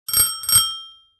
Download Free Service Bell Sound Effects | Gfx Sounds
Metal-bike-bell-ring.mp3